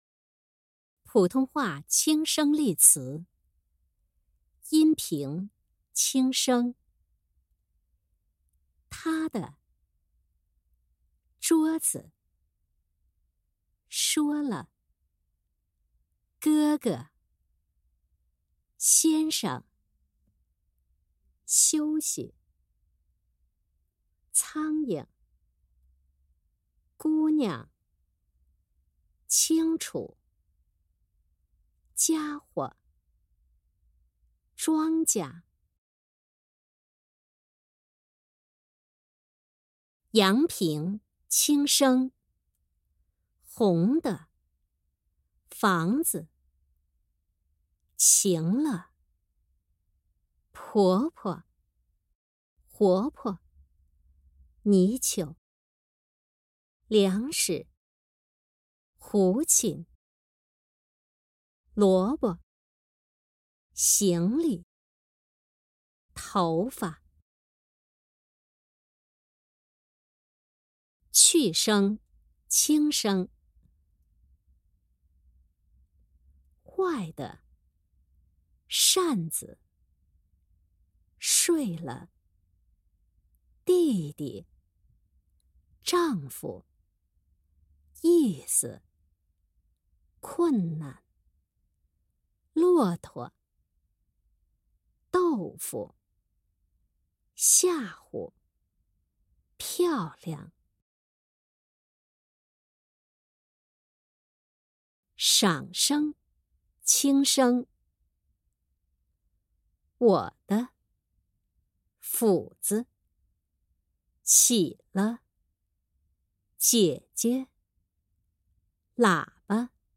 1.第十三讲轻声例词